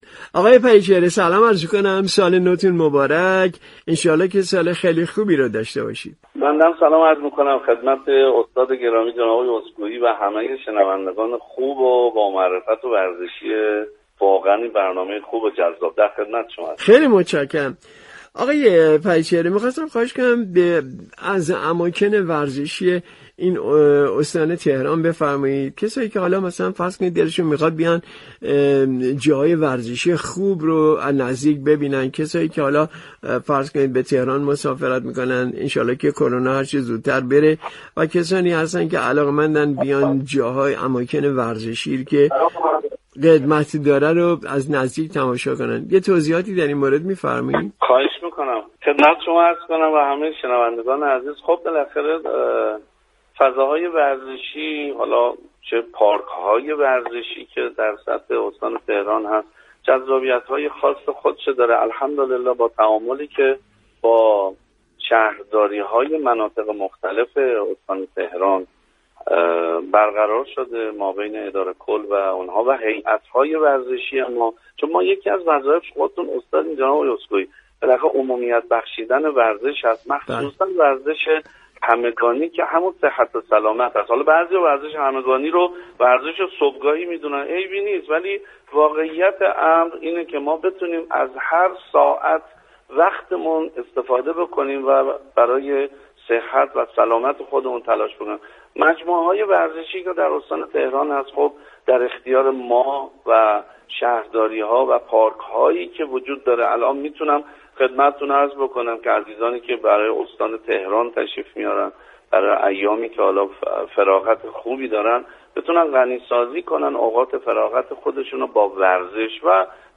به گزارش رادیو ورزش؛ نصرالله پریچهره، مدیركل ورزش و جوانان استان تهران در برنامه "ایران ما" اماكن ورزشی پایتخت كه افراد علاقمند می توانند در ایام تعطیلات نوروز از آنها دیدن فرمایند را معرفی كرد. شما مخاطب محترم می توانید از طریق فایل صوتی پیوست شنونده گفتگوی رادیو ورزش با مدیركل ورزش و جوانان استان تهران باشید.